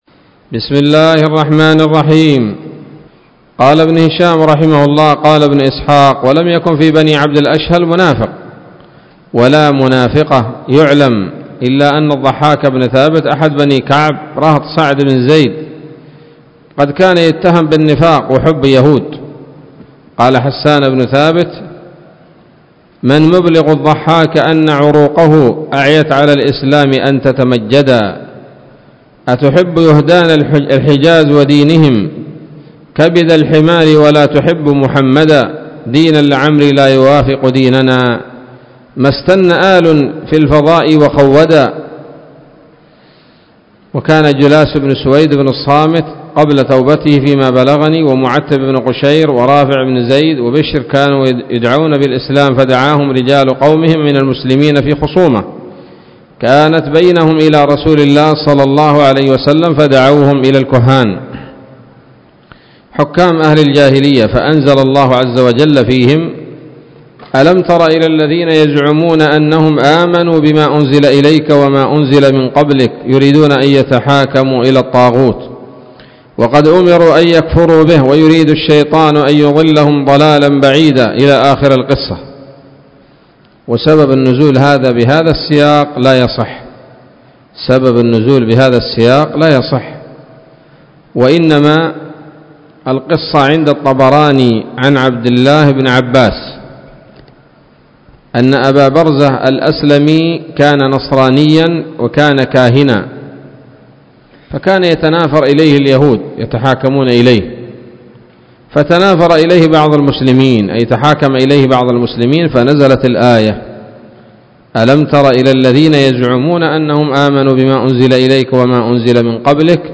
الدرس السادس والثمانون من التعليق على كتاب السيرة النبوية لابن هشام